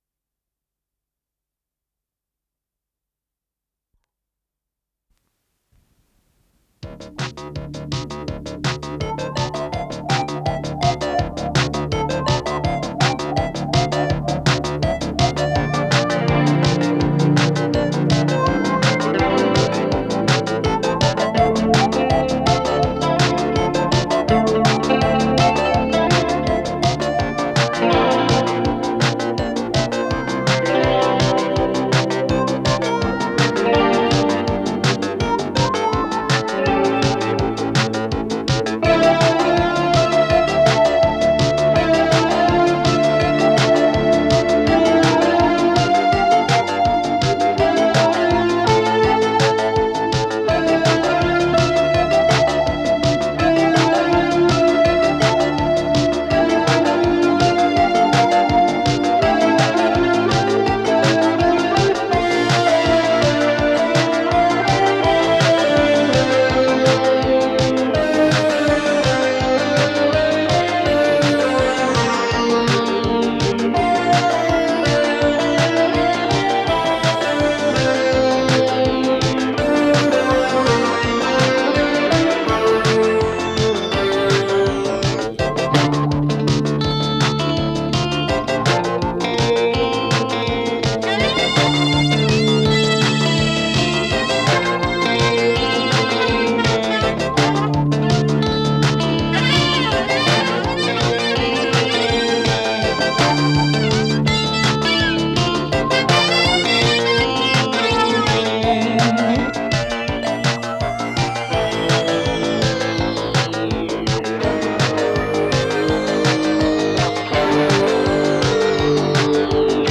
с профессиональной магнитной ленты
ПодзаголовокИнструментальная пьеса